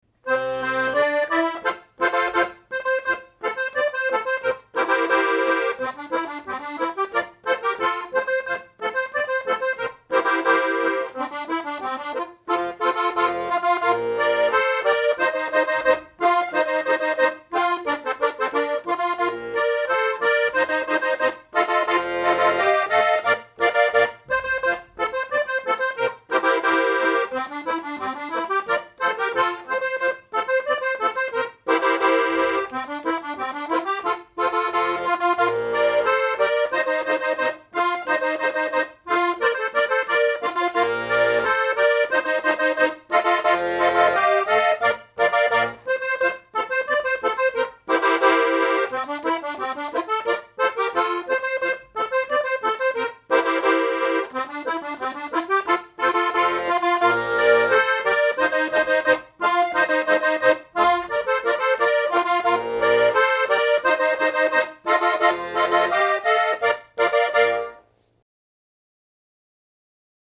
Potovčena - danse (mp3)